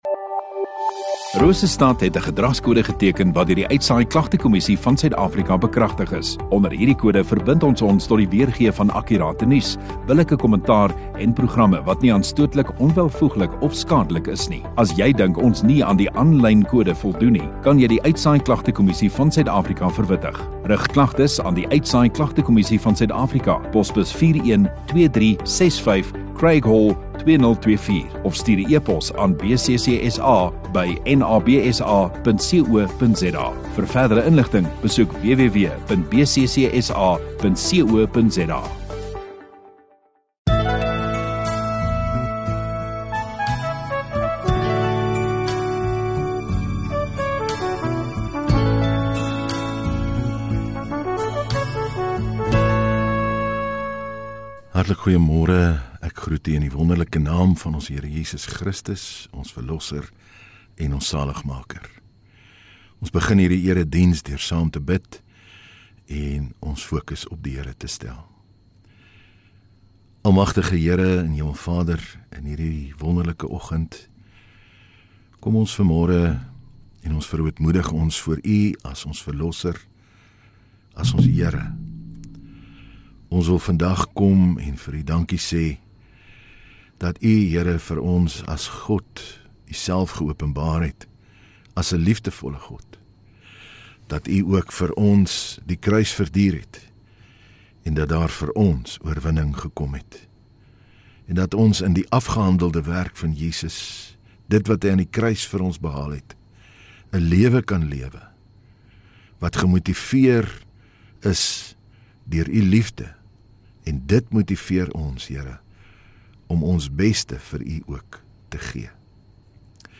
13 Apr Sondagoggend Erediens